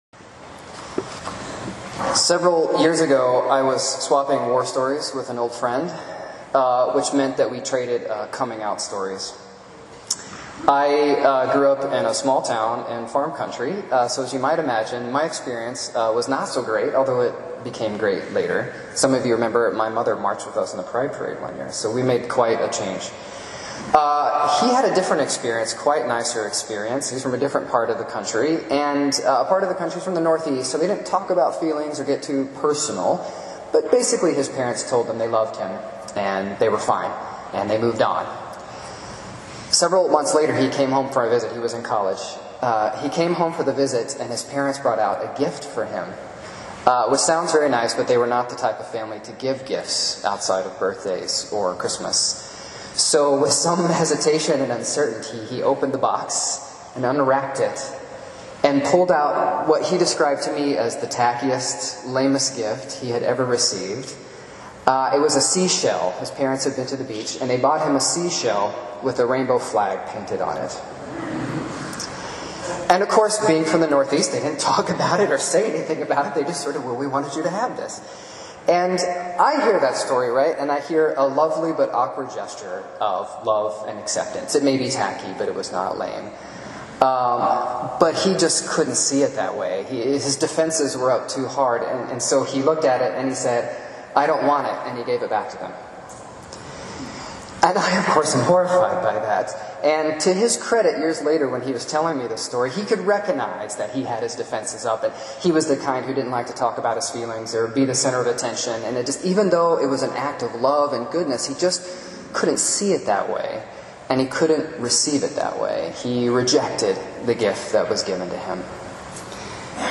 Recent Sermons – Page 45
Recent sermons from St. Thomas Parish - Dupont Circle